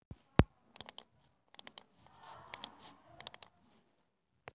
描述：单击“捕捉按钮”
Tag: 点击 按钮